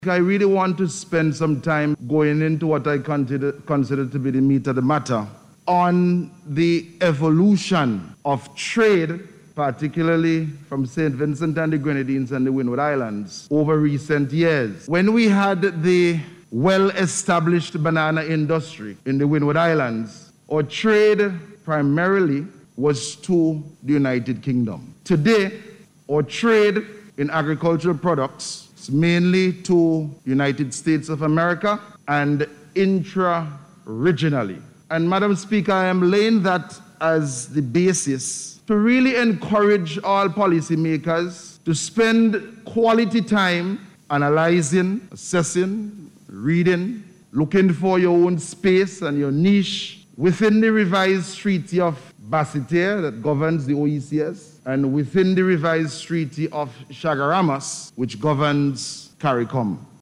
The Agriculture minister made the call to action in Parliament this morning where he contributed to the debate on the amendment of the Customs Excise Tax Bill.